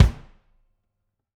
kick3.mp3